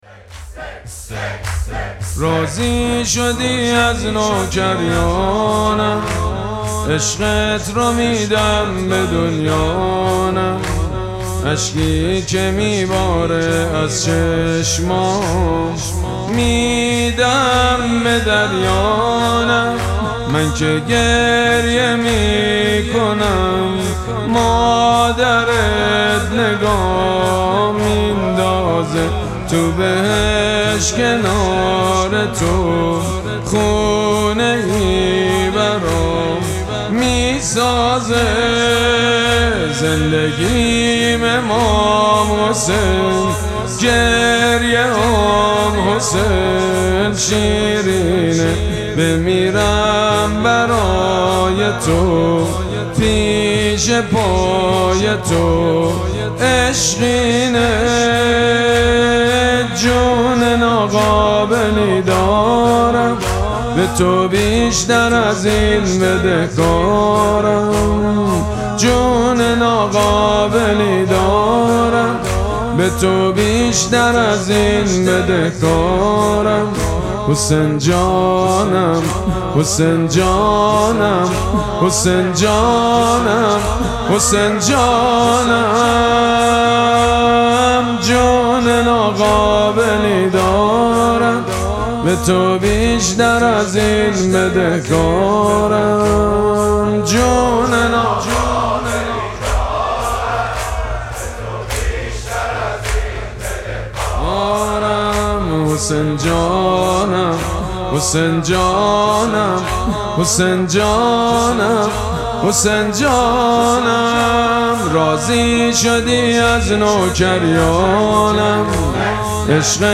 مراسم مناجات شب ششم ماه مبارک رمضان
حسینیه ریحانه الحسین سلام الله علیها
مداح
حاج سید مجید بنی فاطمه